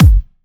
VFH2 130BPM Comboocha Kick.wav